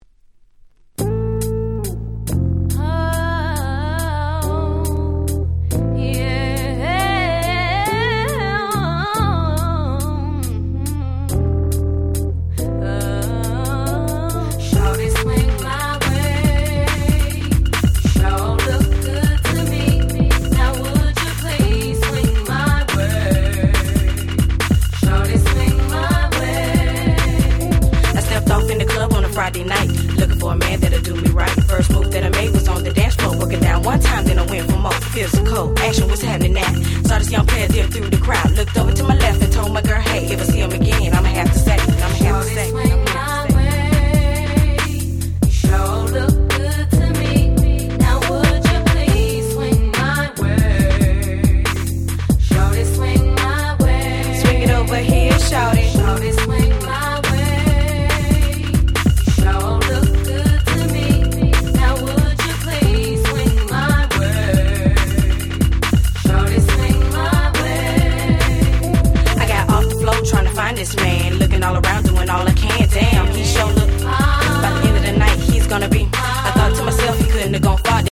98' Super Hit Miami Bass R&B !!